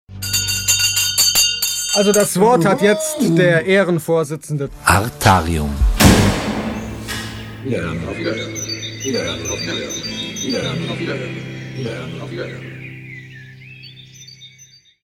Artarium-Auf-Wiederhoeren-Glocke.mp3